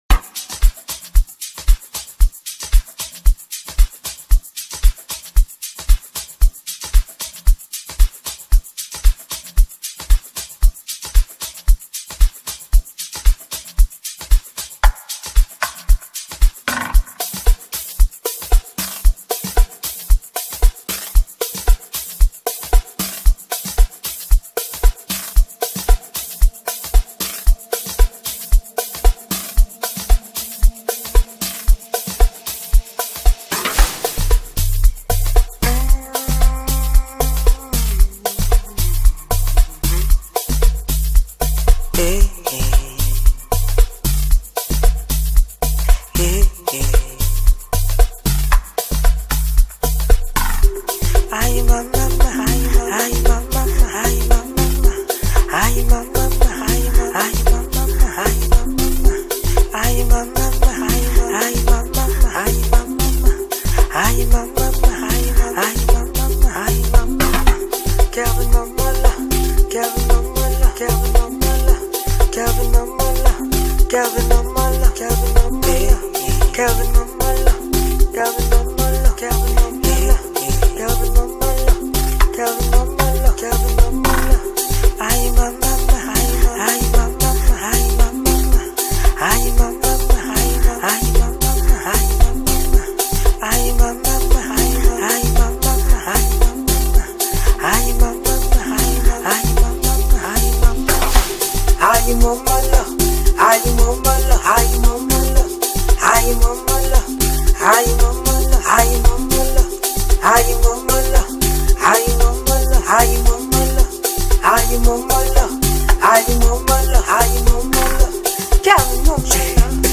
When you talk about the Amapiano genre